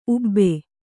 ♪ ubbe